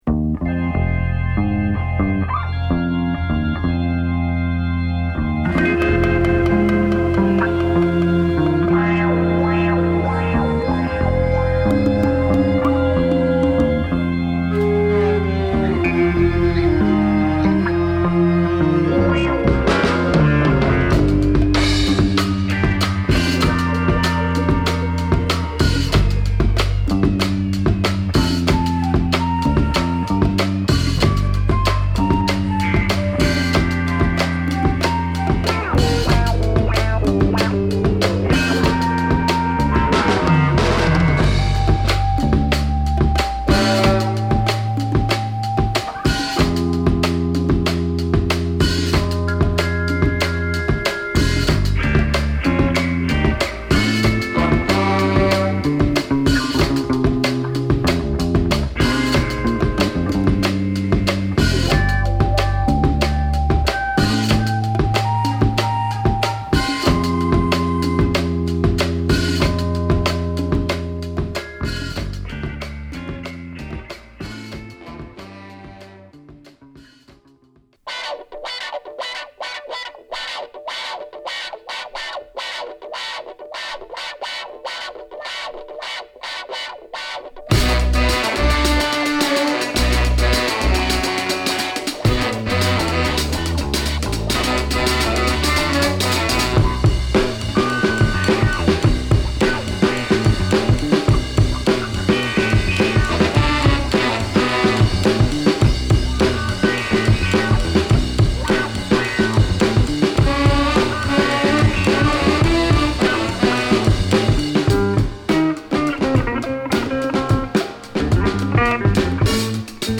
こちらはインスト盤！
ブレイクビーツからファンキーソウルまでまさにDJ、サンプリングネタの宝庫!